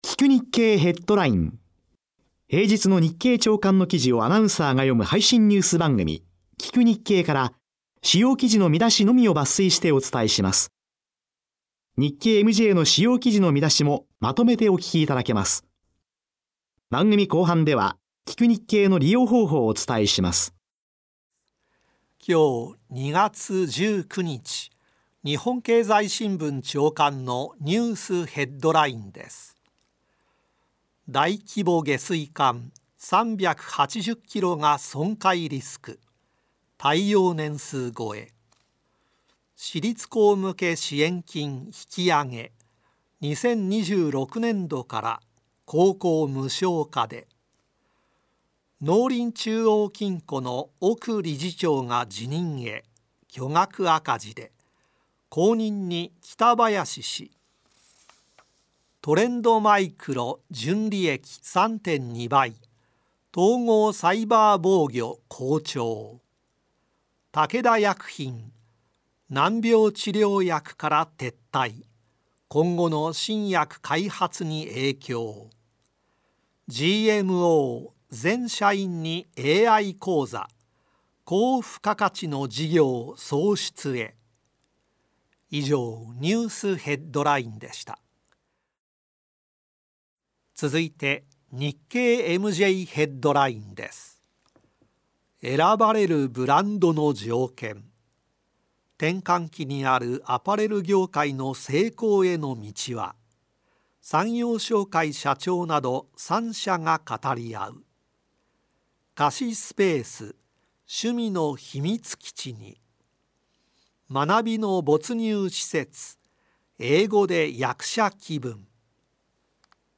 … continue reading 3196 episoder # ビジネスニュース # 日本 経済学 # NIKKEI RADIO BROADCASTING CORPORATION # ビジネス # ニュース